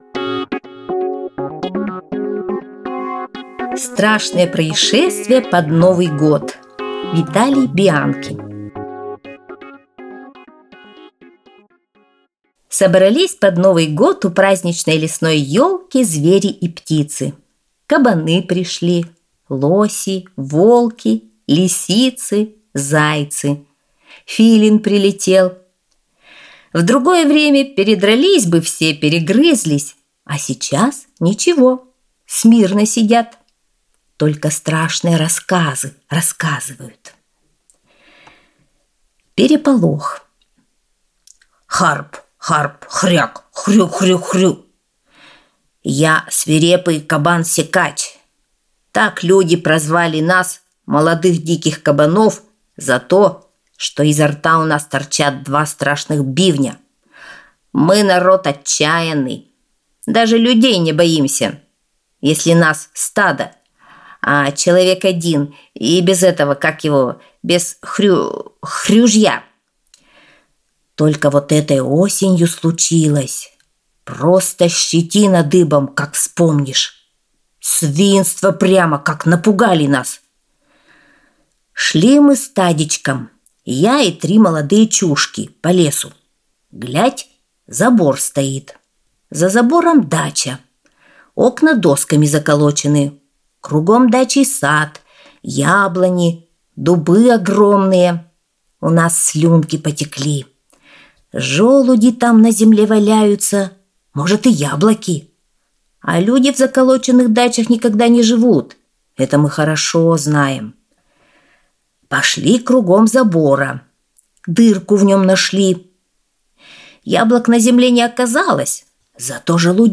Страшные происшествия под Новый год - аудиосказка Бианки